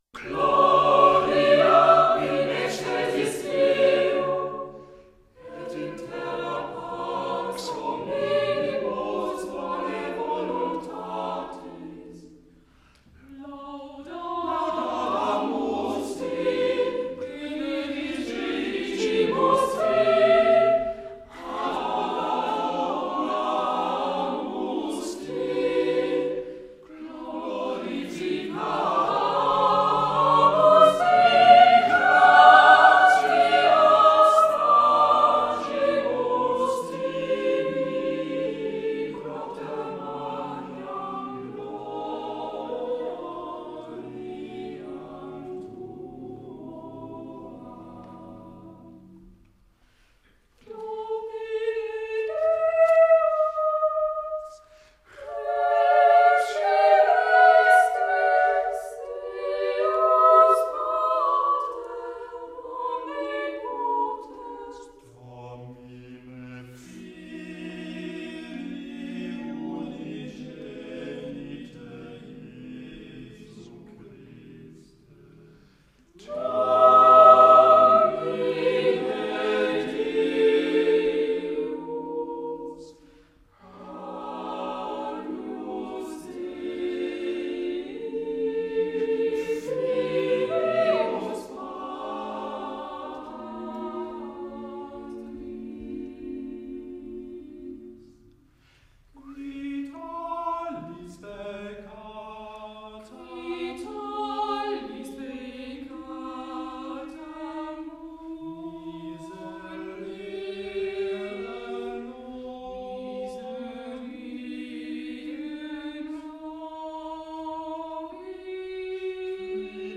Neues Vokalensemble der Musikhochschule Hannover